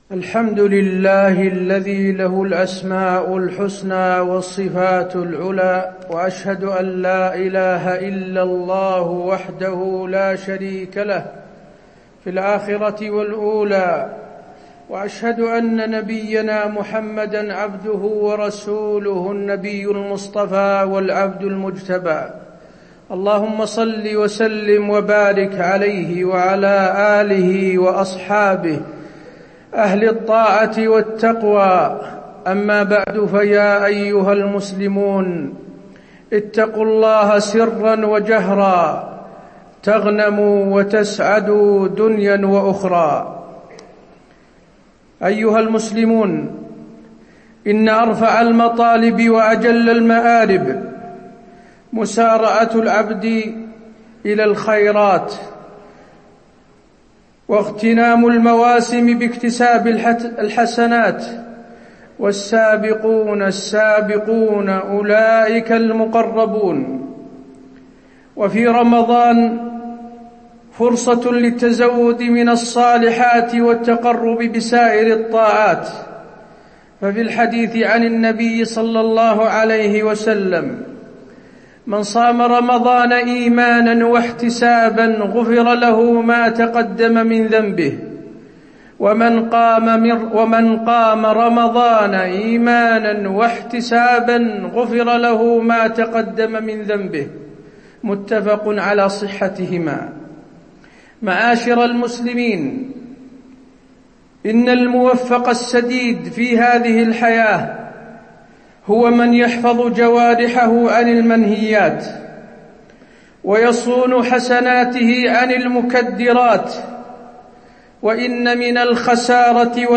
تاريخ النشر ٧ رمضان ١٤٣٨ هـ المكان: المسجد النبوي الشيخ: فضيلة الشيخ د. حسين بن عبدالعزيز آل الشيخ فضيلة الشيخ د. حسين بن عبدالعزيز آل الشيخ المفلس في رمضان